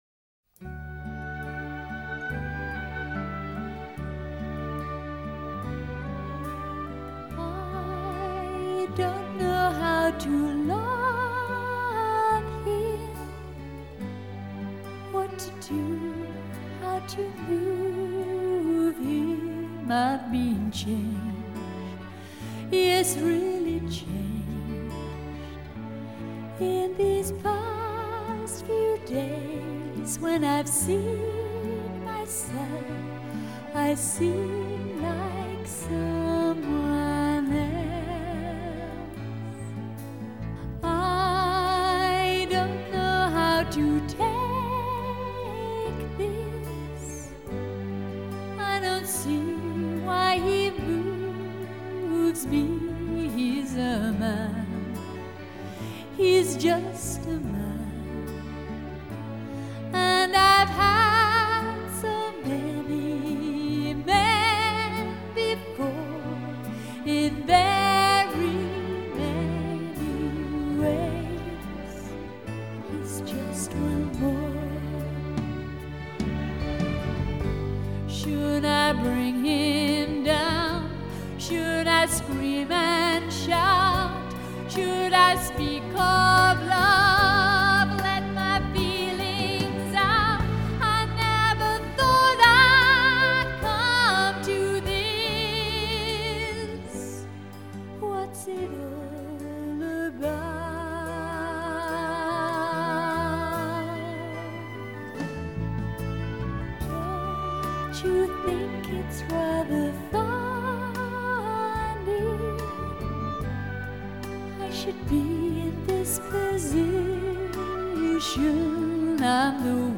明朗澄澈的录音效果